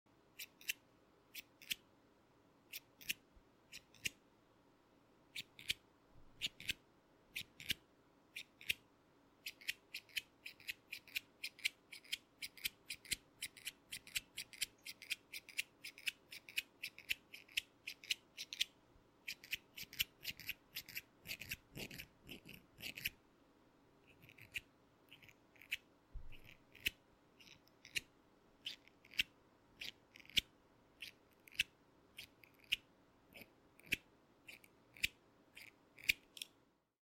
Scissor Snipping Sounds | ASMR sound effects free download
ASMR Mp3 Sound Effect Scissor Snipping Sounds | ASMR with binaural audio.